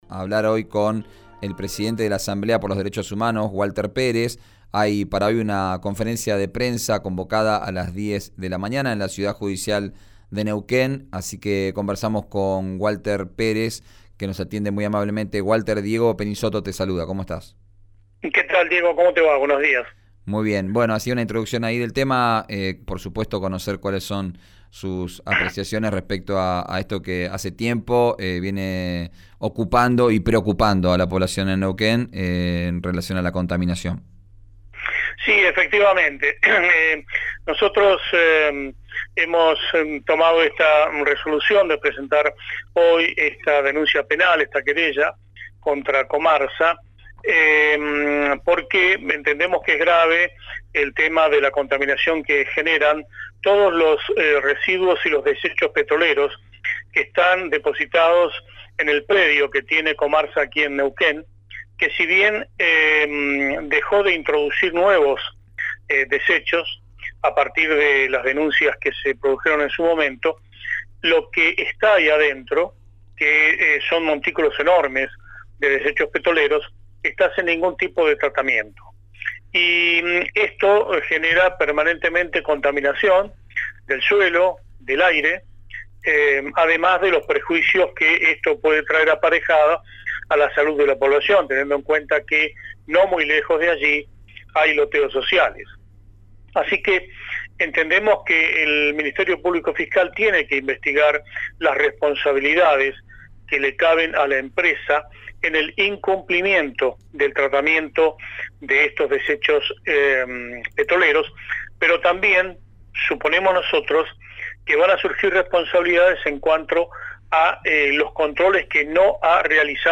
Escuchá la entrevista completa en RÍO NEGRO RADIO.